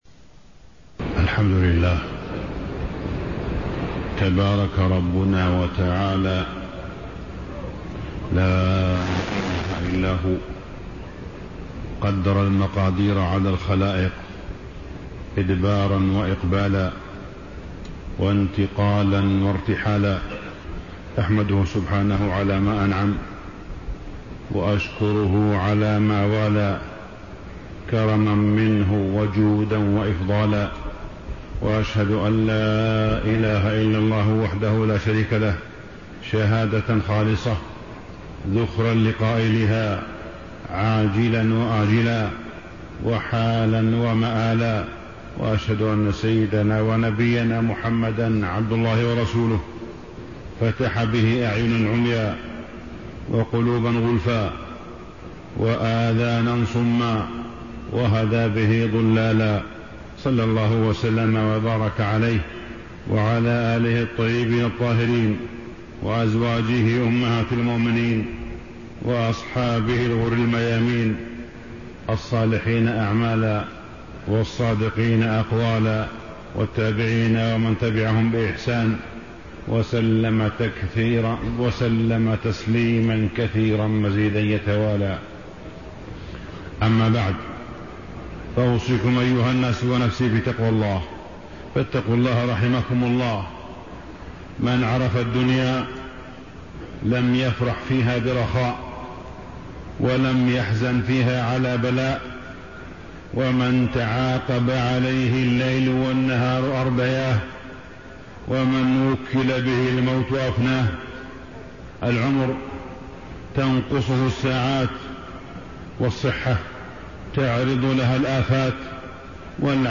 تاريخ النشر ٢٨ ذو القعدة ١٤٣٤ هـ المكان: المسجد الحرام الشيخ: معالي الشيخ أ.د. صالح بن عبدالله بن حميد معالي الشيخ أ.د. صالح بن عبدالله بن حميد أخلاقنا في الحروب